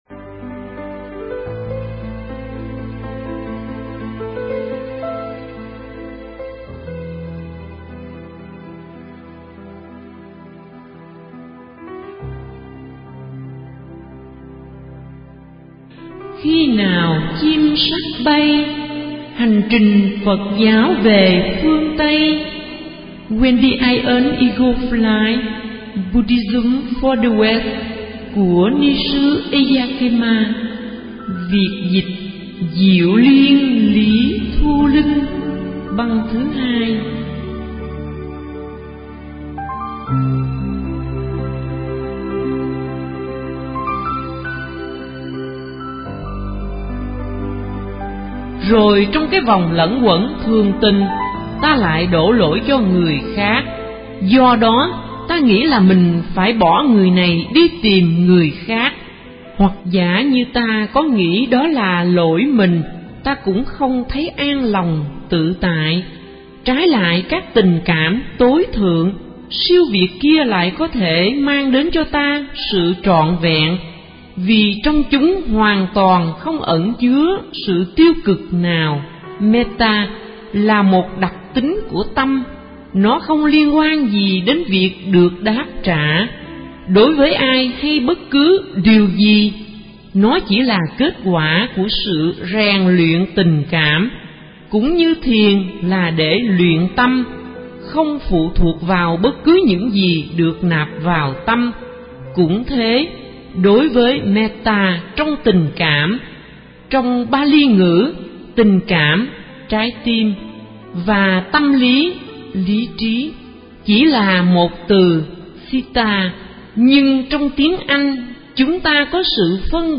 Truyện Đọc Khi Nào Chim Sắt Bay - Diệu Liên Lý Thu Linh